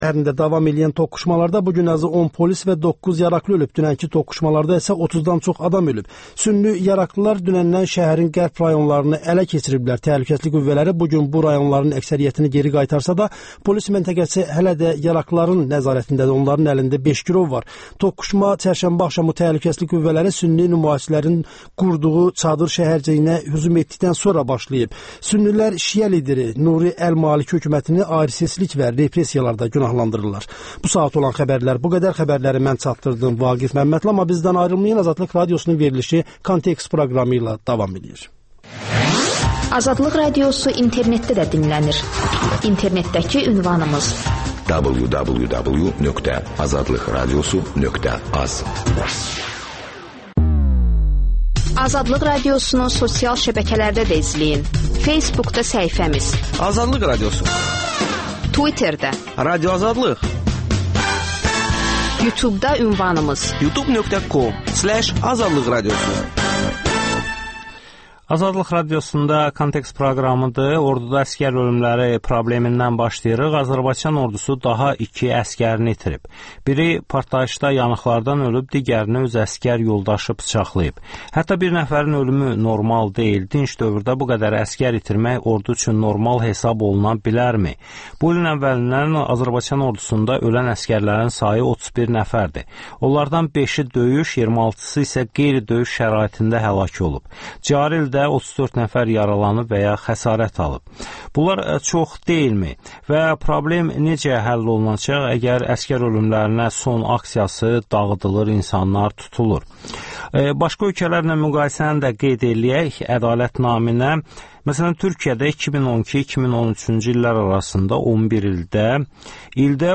Kontekst - Bina sürüşdürməsi: Bakıda sorğu